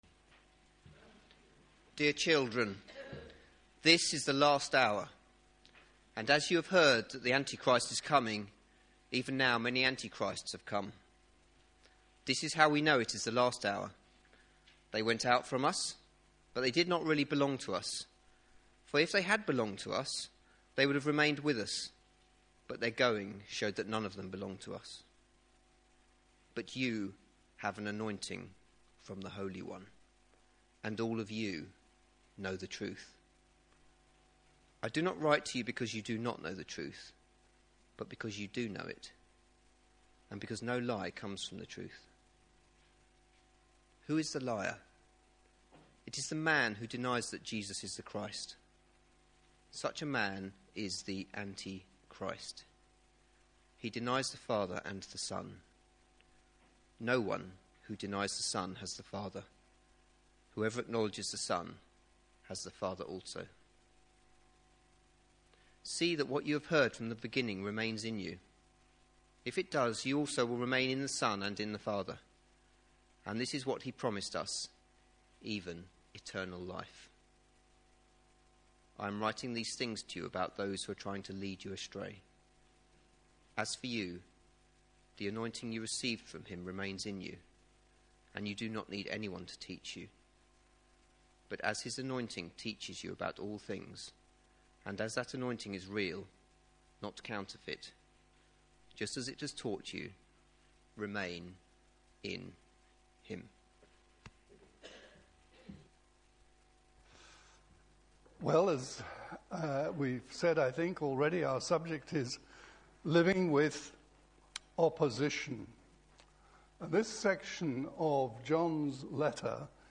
Media for Sunday Service on Sun 03rd Feb 2013 10:00